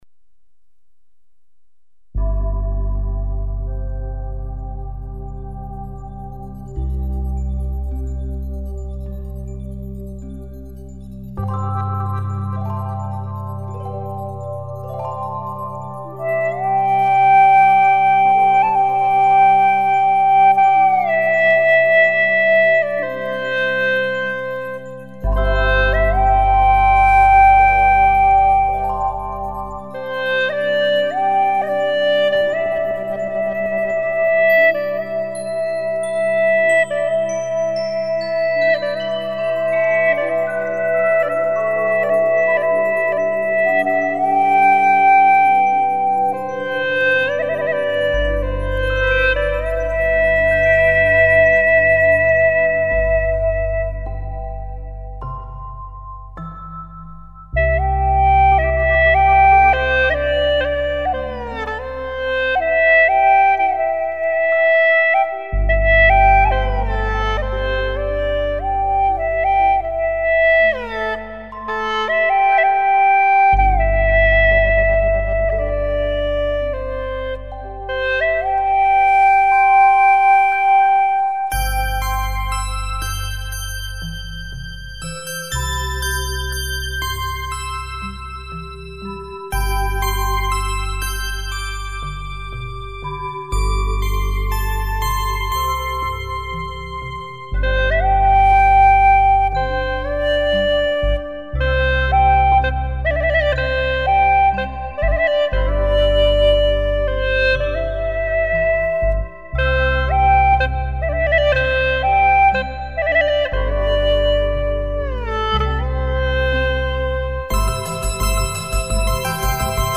调式 : C
独奏
第一段舒缓的节奏